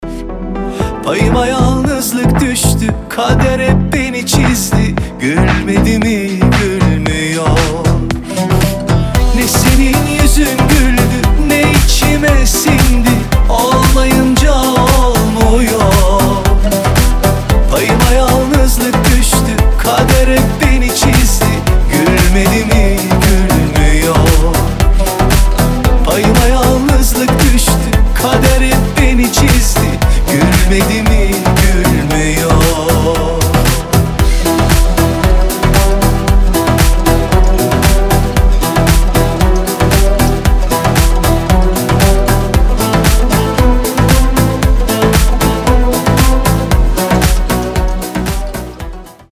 • Качество: 320, Stereo
поп
гитара
мелодичные
восточные